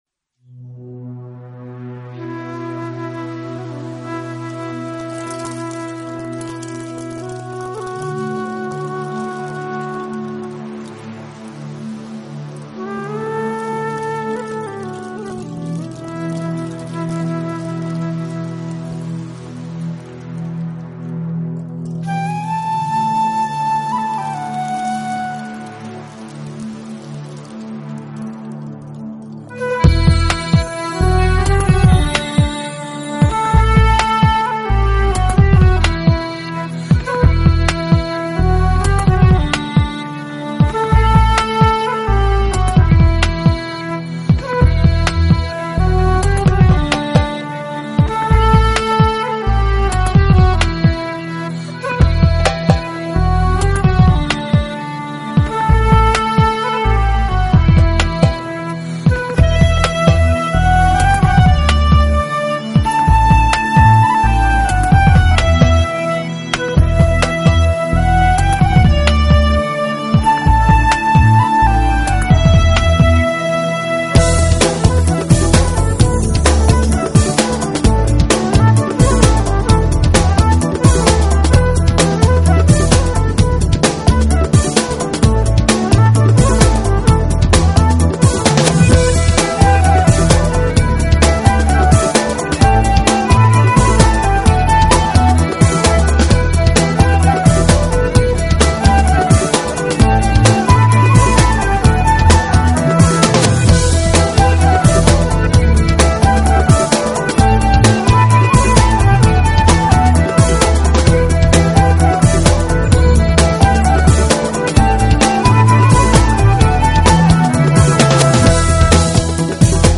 改良以后的“电子风笛”合作演奏出来的，而在他后期的作品里，电子风笛作为了主
笛手再吹出旋律时，每个音符就都有了一组不同的泛音，混成一体后悠扬高亢，令